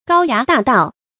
高牙大纛 注音： ㄍㄠ ㄧㄚˊ ㄉㄚˋ ㄉㄠˋ 讀音讀法： 意思解釋： 三代軍隊里的大旗。指軍中的旗幟。比喻聲勢顯赫。